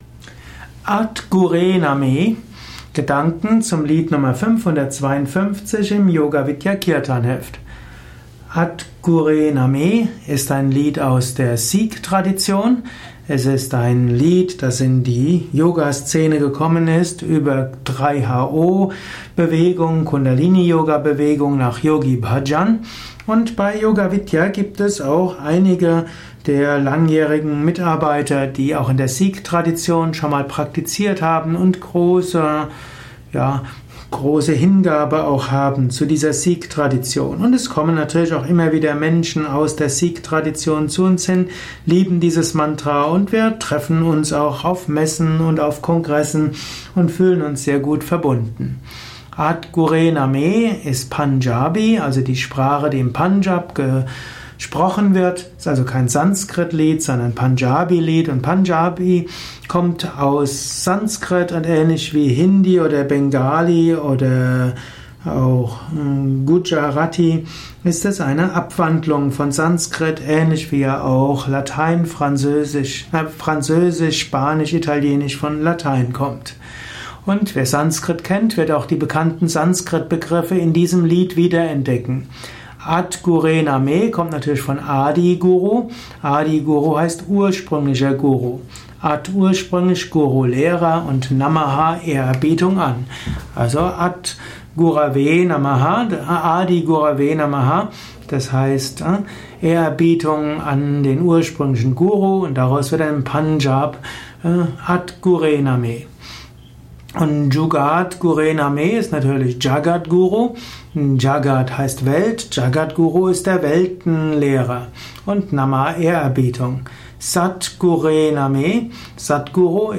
der Nr. 552 im Yoga Vidya Kirtanheft , Tonspur eines Kirtan